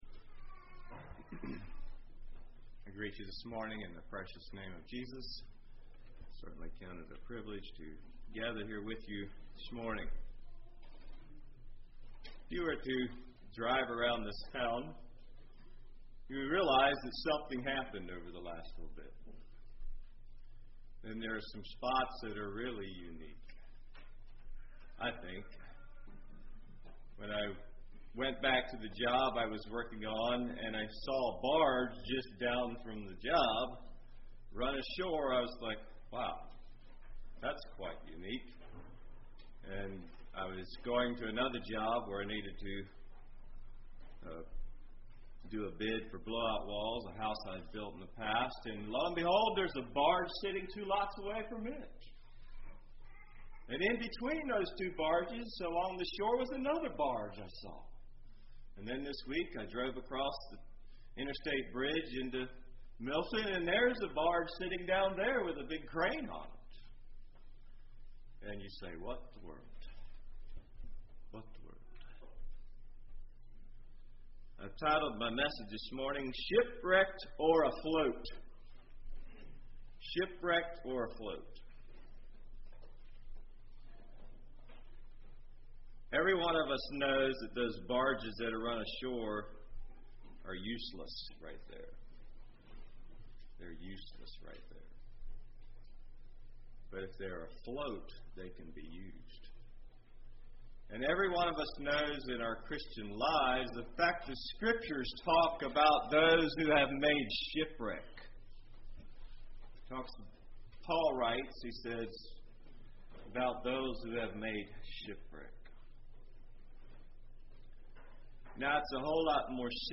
Congregation: Pensacola Speaker